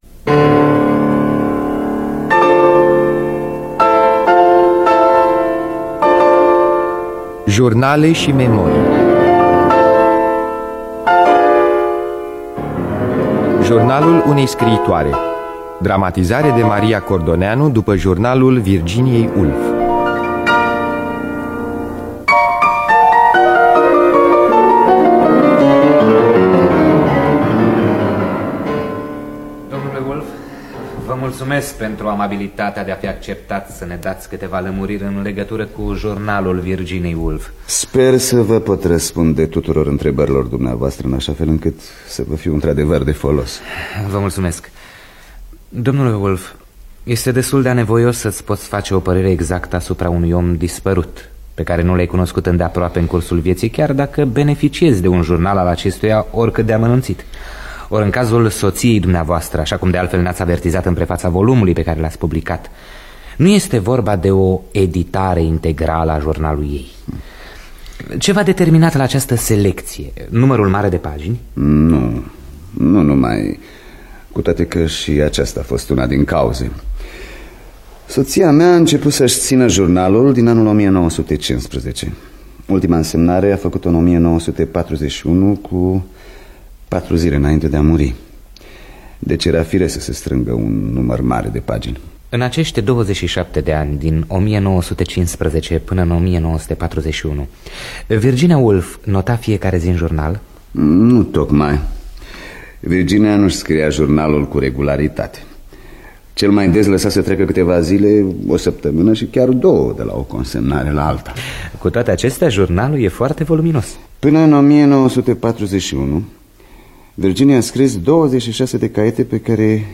Biografii, Memorii: Virginia Woolf – Jurnalul Unei Scriitoare (1982) – Teatru Radiofonic Online